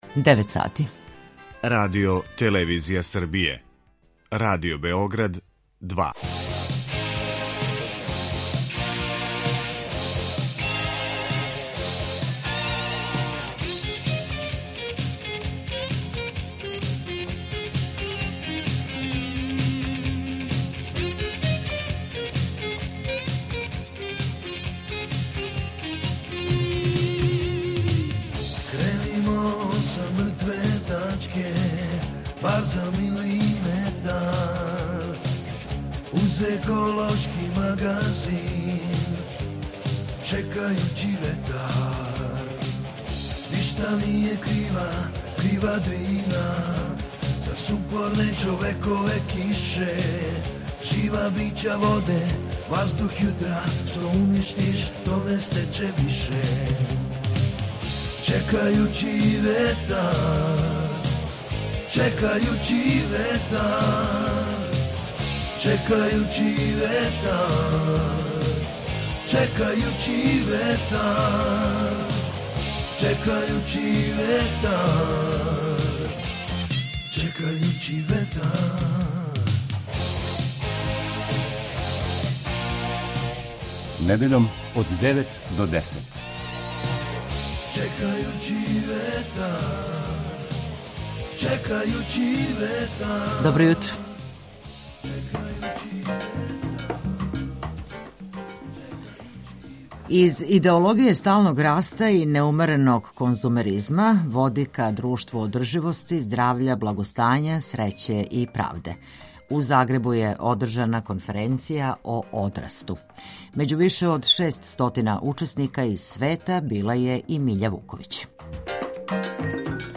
Фото: Радио Београд 2 ПРЕСЛУШАЈ: ОВДЕ Чекајући ветар - еколошки магазин Радио Београда 2 који се бави односом човека и животне средине, човека и природе.